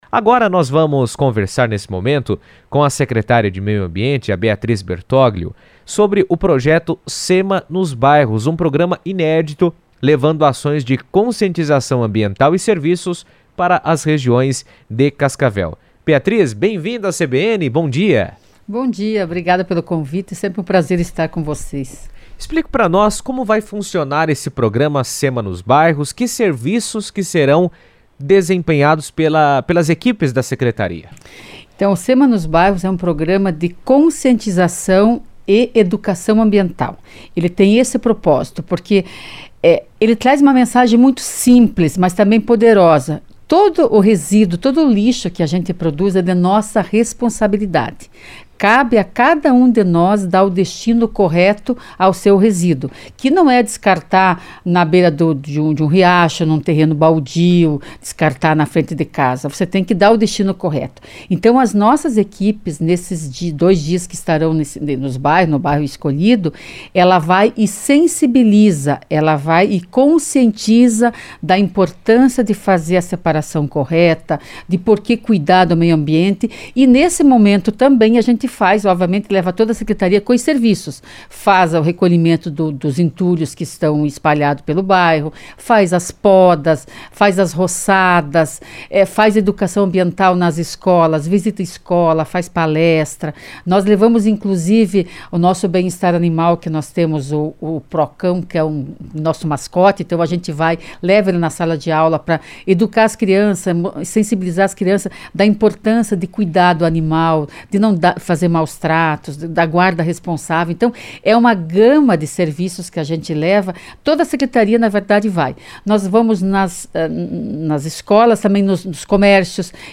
Cascavel lançou um programa inédito de conscientização ambiental que será levado a todos os bairros da cidade, com ações voltadas ao descarte correto de resíduos, preservação dos espaços públicos e incentivo à participação comunitária. Em entrevista à CBN, a secretária de Meio Ambiente, Beatriz Bertoglio, ressaltou que a iniciativa busca aproximar a população das práticas sustentáveis e fortalecer o sentimento de responsabilidade coletiva.